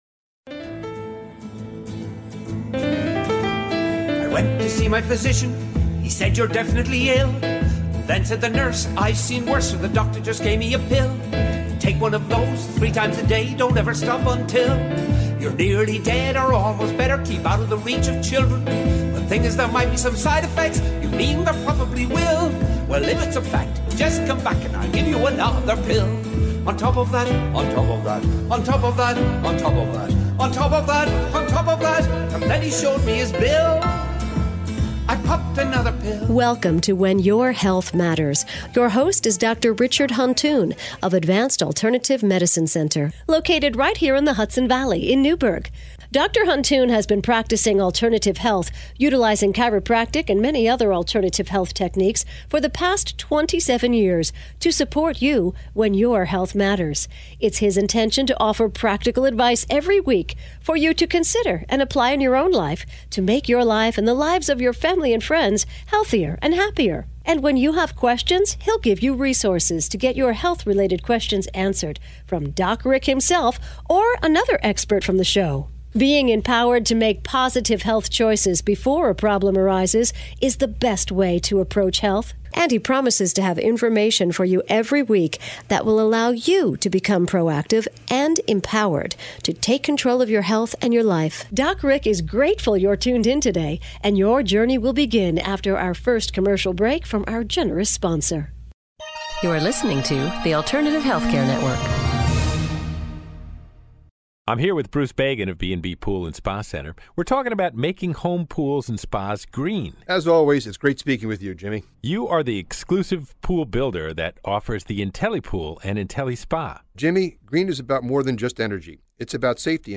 Part 1 of a conversation on how hands on healing work follows God's natural laws and is consistent with Christian teachings